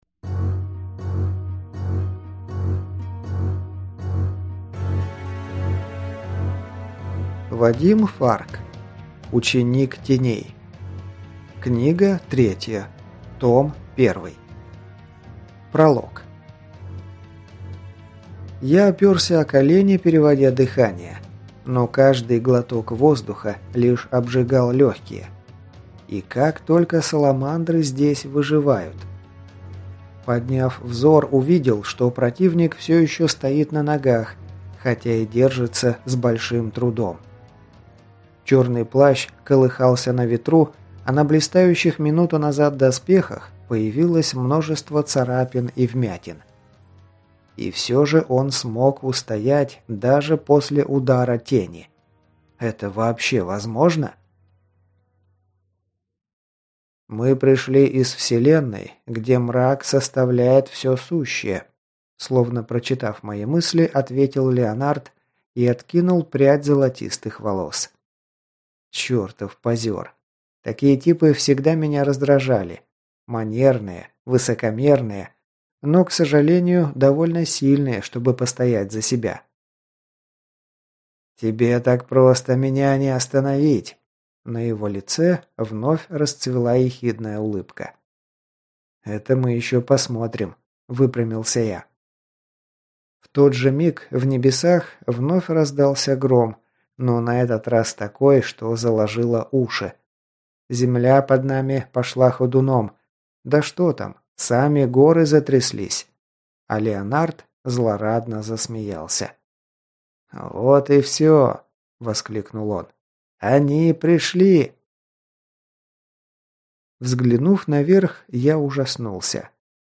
Аудиокнига Ученик Теней. Книга 3. Том 1 | Библиотека аудиокниг